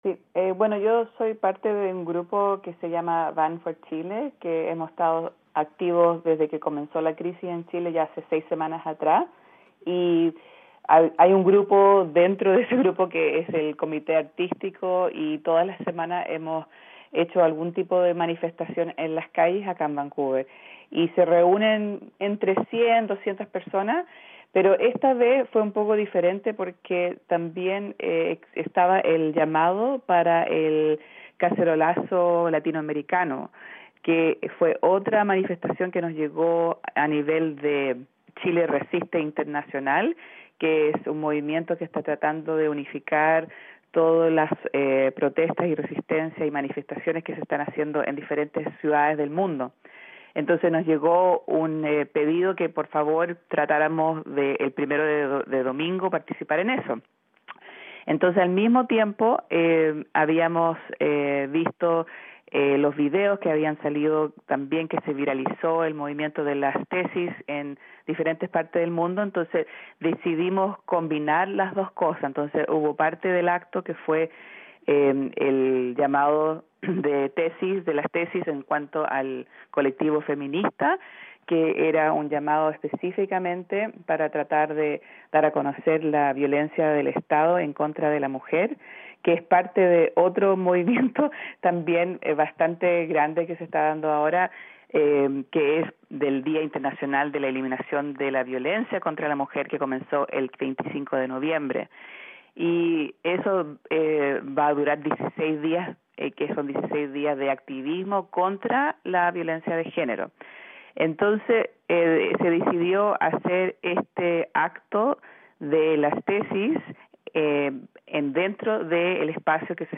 Conversación con una de las organizadoras